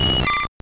Cri de Poissirène dans Pokémon Or et Argent.